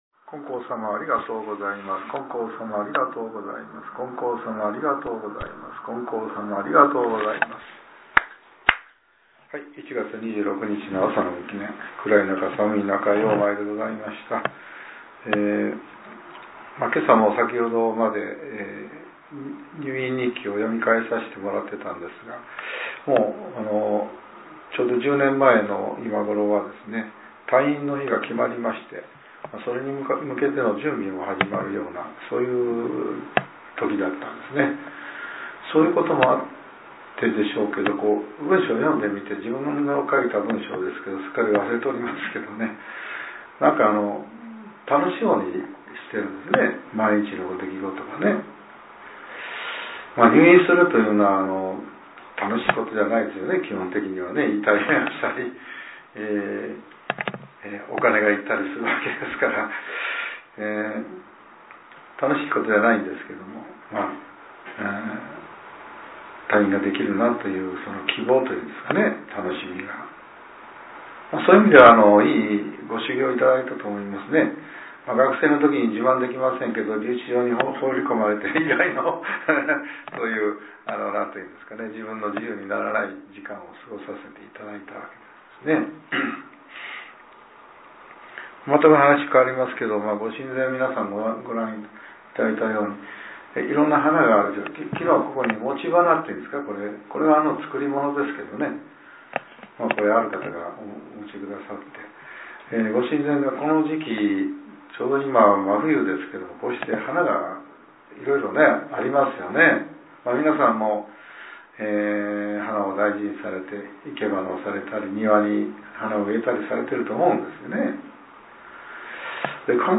令和８年１月２６日（朝）のお話が、音声ブログとして更新させれています。